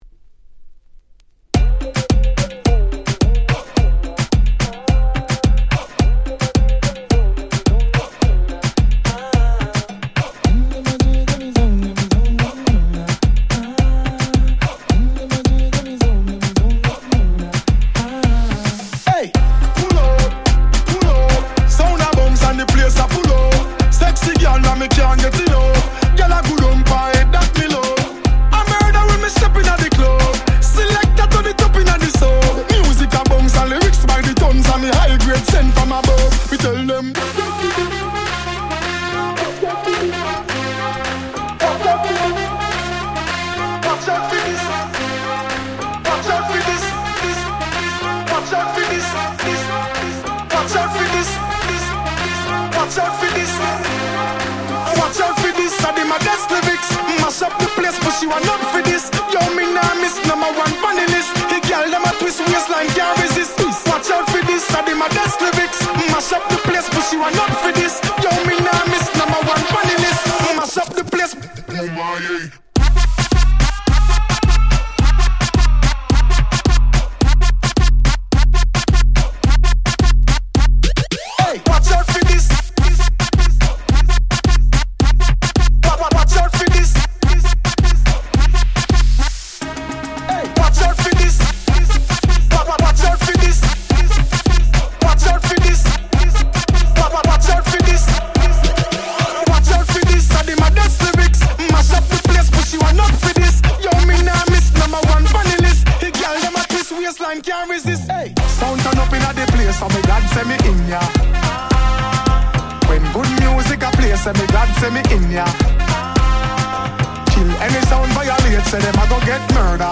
13' Super Hit Moombahton !!